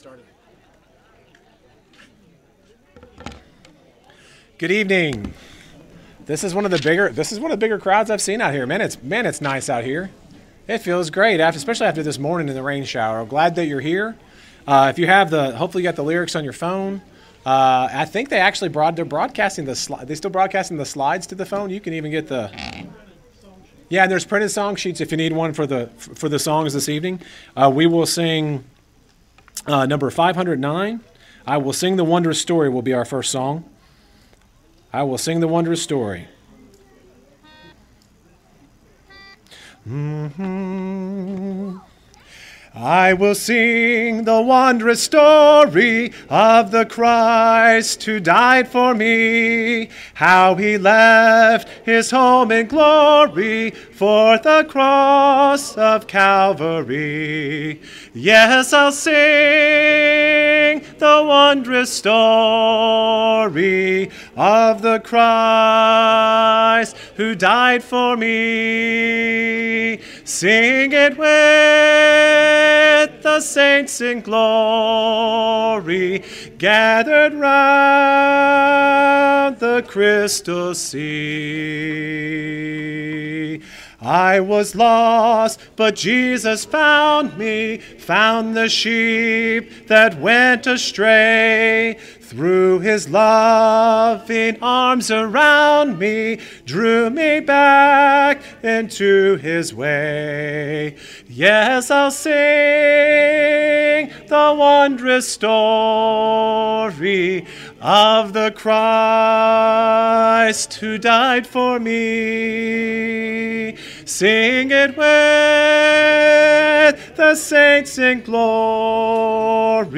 Proverbs 3:11, English Standard Version Series: Sunday PM Service